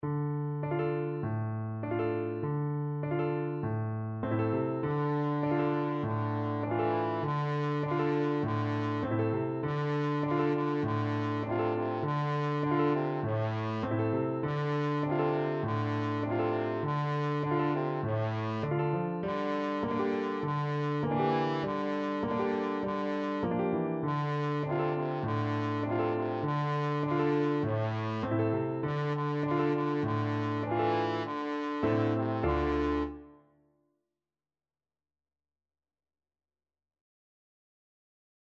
Trombone
Moderato
D minor (Sounding Pitch) (View more D minor Music for Trombone )
4/4 (View more 4/4 Music)